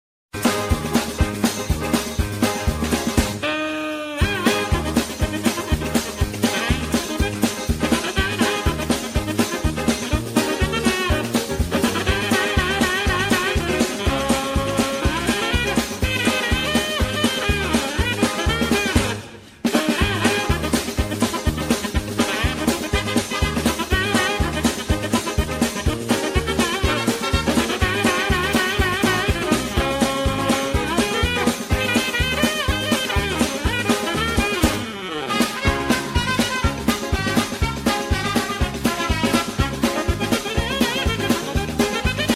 HEAVY EQUIPMENT OPERATOR 'MOPS UP' sound effects free download
HEAVY EQUIPMENT OPERATOR 'MOPS UP' THE TRASH AT A DUMP SITE WITH A MATTRESS